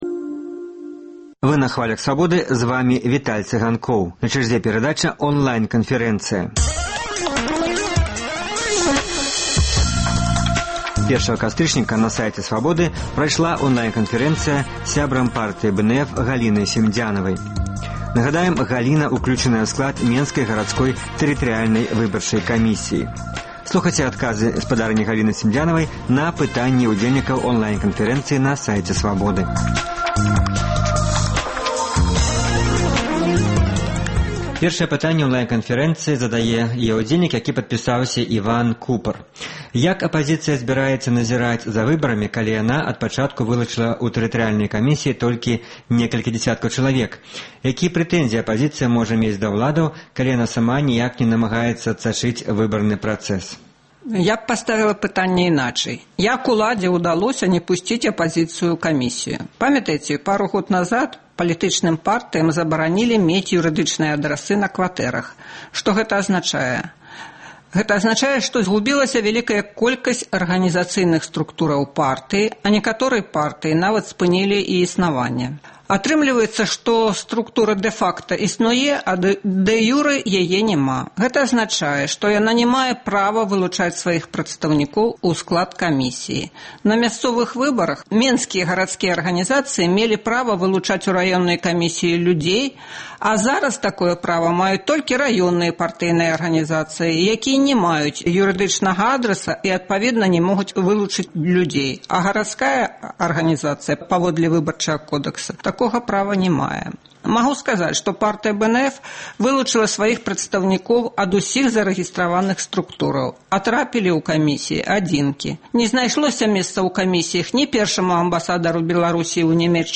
Онлайн- канфэрэнцыя